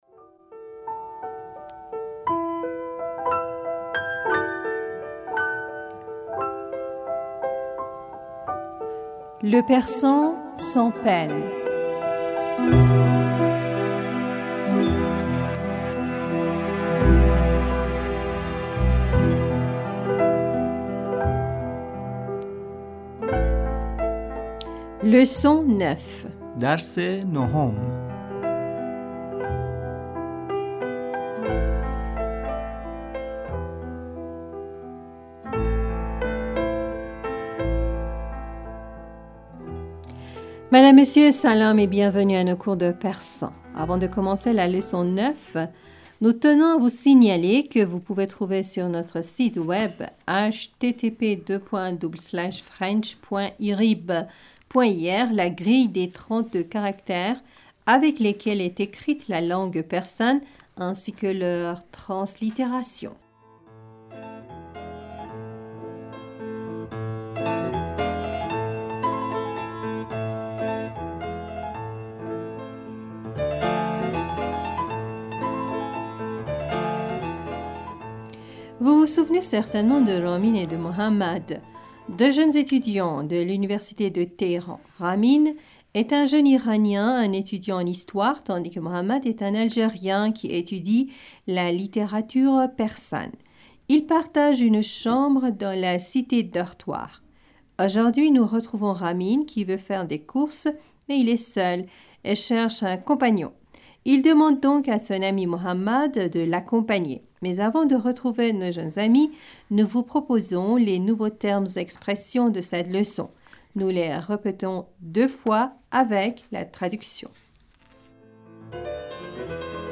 Madame, Monsieur, Salam et bienvenus à nos cours de persan.